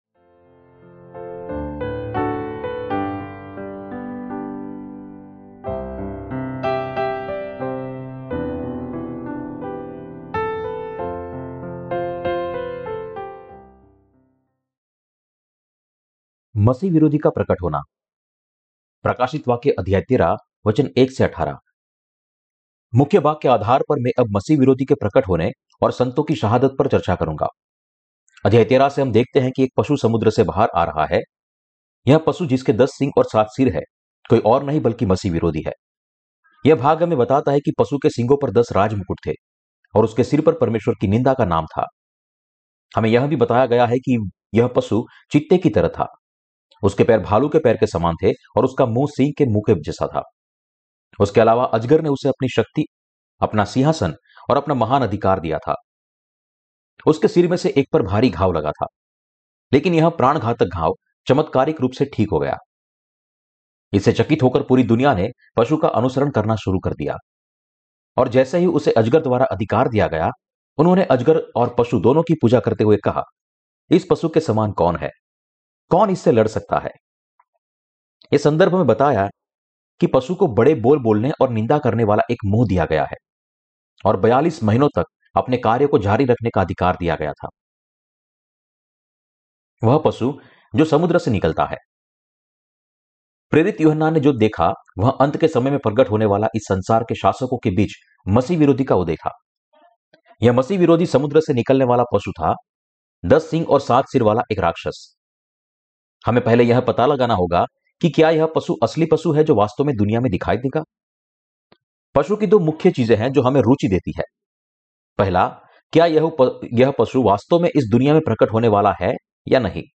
प्रकाशितवाक्य की किताब पर टिप्पणी और उपदेश - क्या मसीह विरोधी, शहादत, रेप्चर और हजार साल के राज्य का समय नज़दीक है? (II) Ch13-2. मसीह विरोधी का प्रगट होना (प्रकाशितवाक्य १३:१-१८) Dec 08 2022 | 00:31:41 1x 00:00 / 00:31:41 Subscribe Share Apple Podcasts Spotify Amazon Music Overcast RSS Feed Share Link Embed